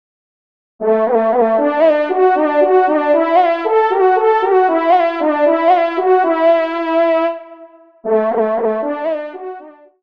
FANFARE